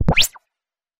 Sample Sound Effects
laser-zap.mp3